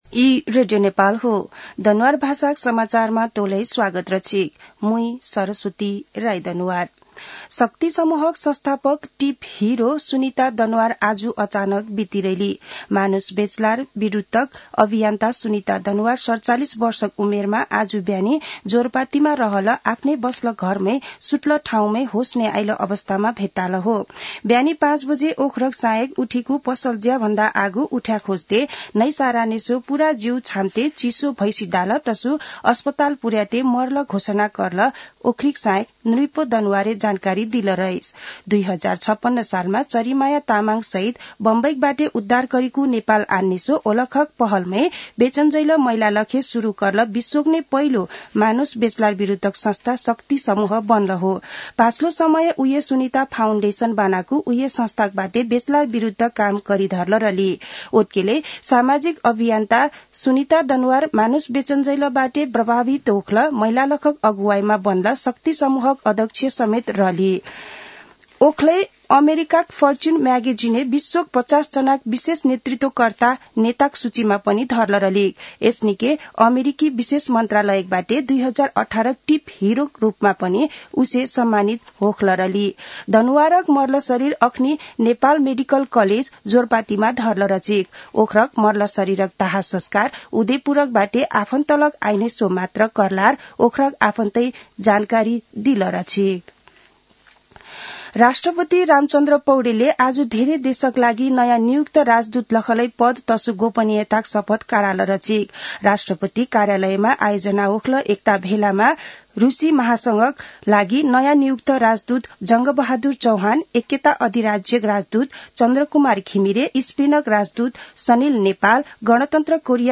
दनुवार भाषामा समाचार : ९ पुष , २०८१